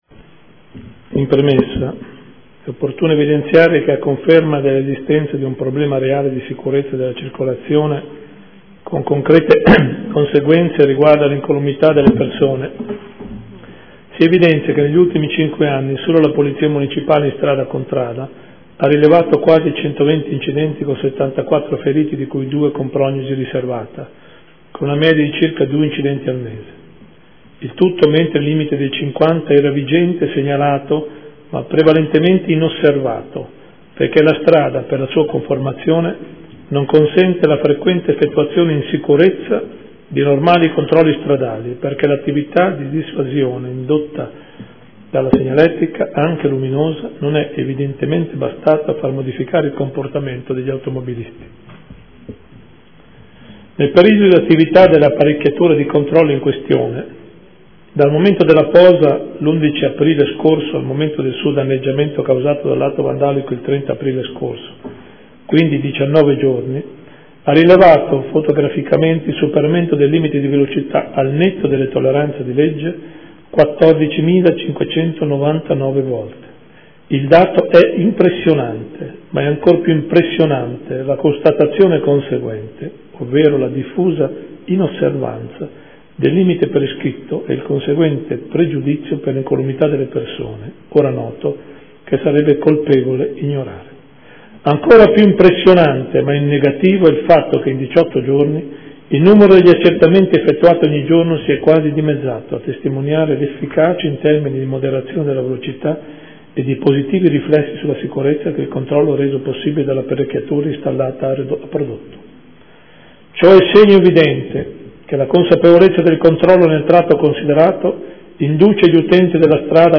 Gian Carlo Muzzarelli — Sito Audio Consiglio Comunale